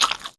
bite-small.wav